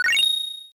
Heal.ogg